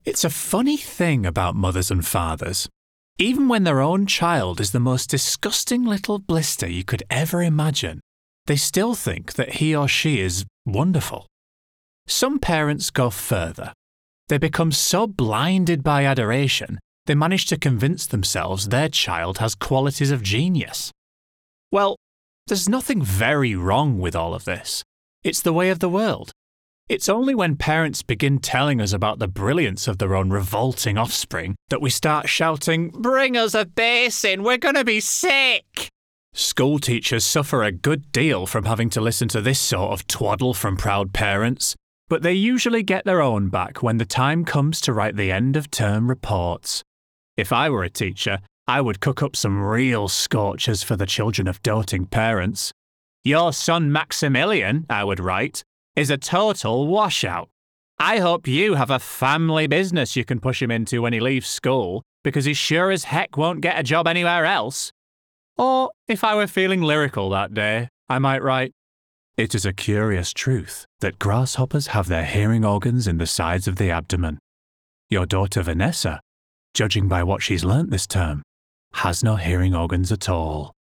Audiobook Showreel
Male
Yorkshire